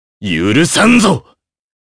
Kain-Vox_Damage_jp_03.wav